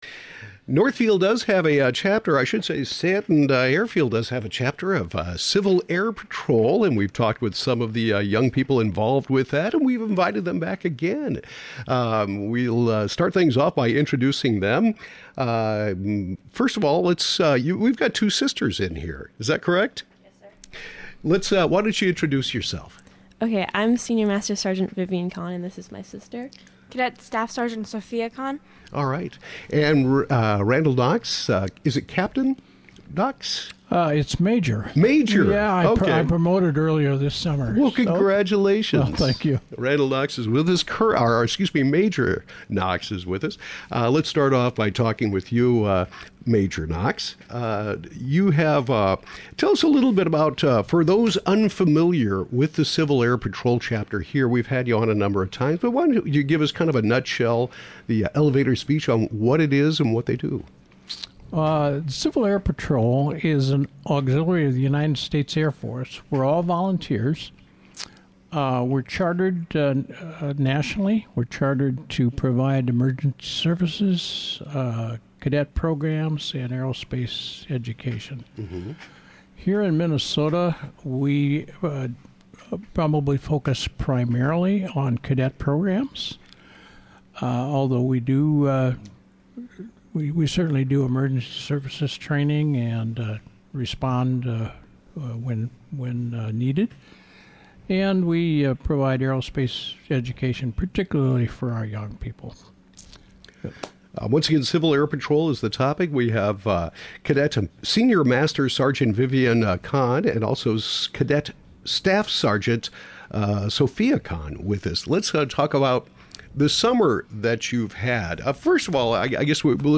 Stanton Civil Air Patrol conversation